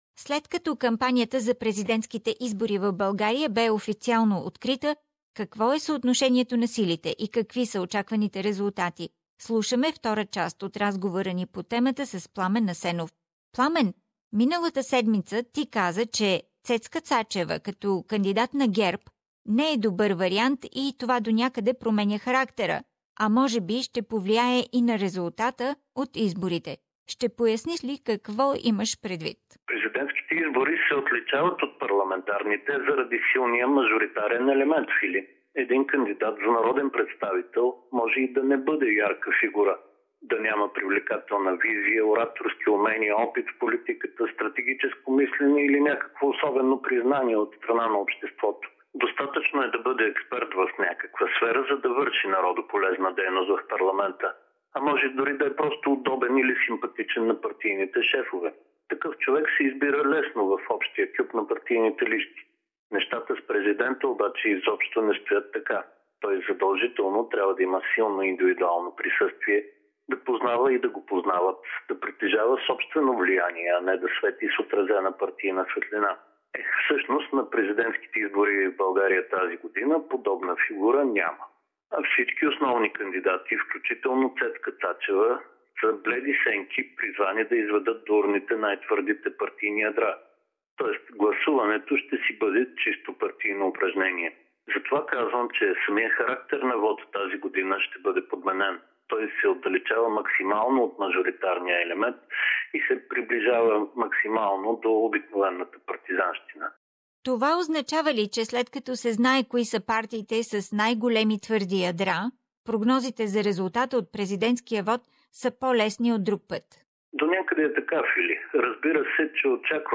Какво е съотношението на силите и какви са очакваните резултати? Политически анализ